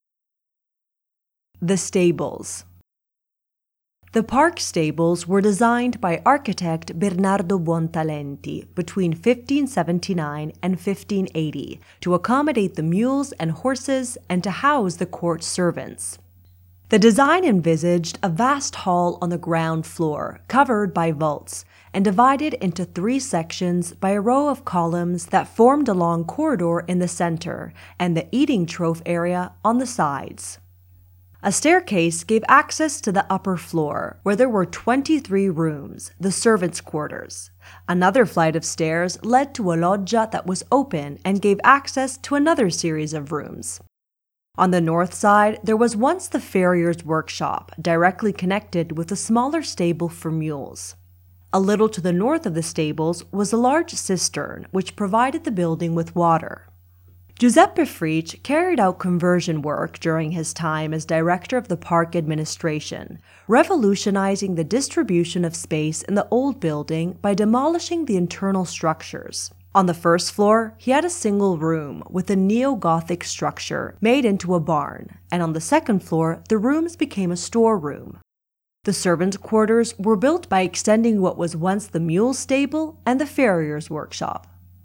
Audioguide of the Medici Park of Pratolino